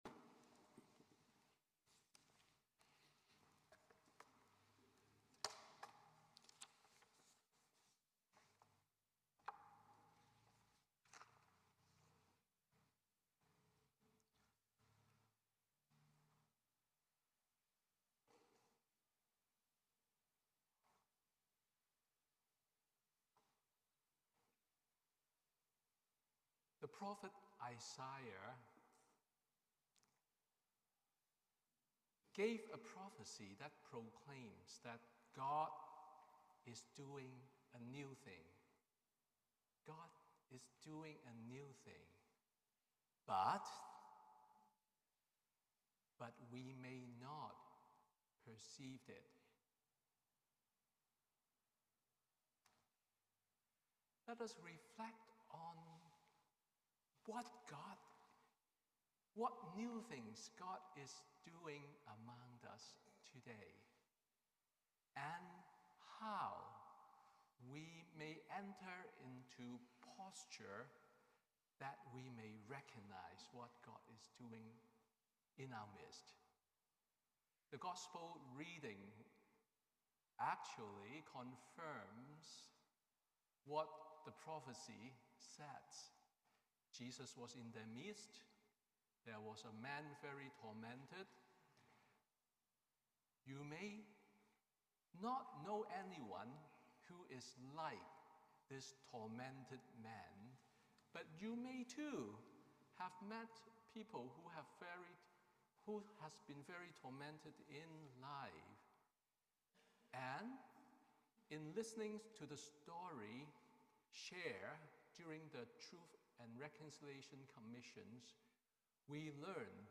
Sermon on National Indigenous Day of Prayer